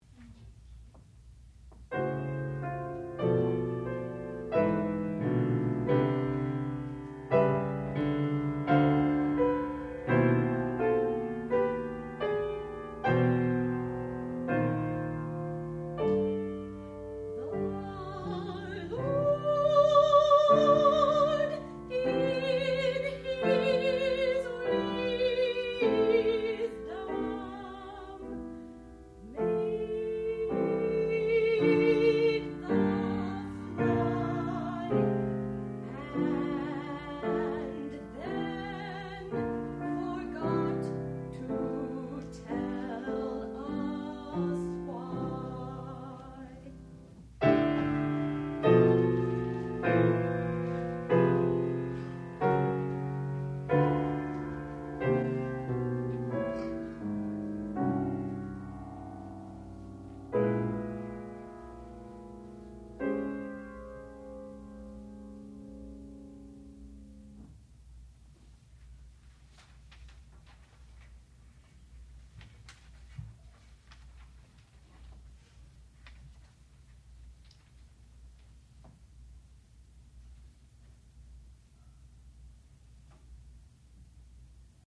sound recording-musical
classical music
soprano
piano
baritone